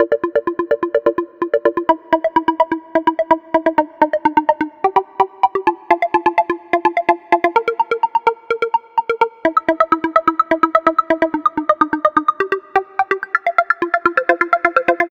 Track 10 - Arp Syncopation 02.wav